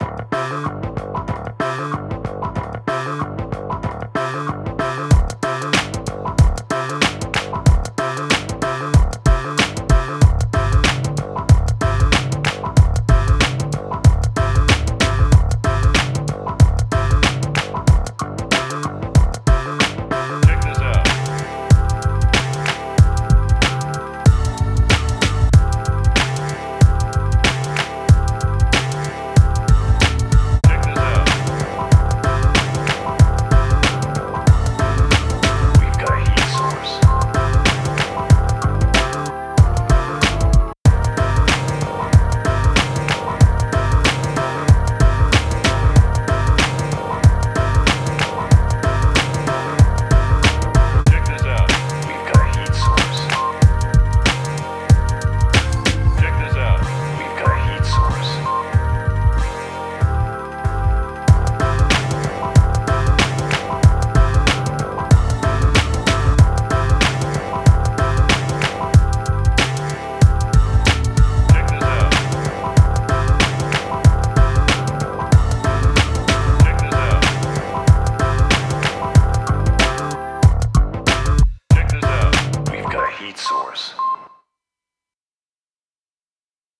All done on an MPC One.
super funky groove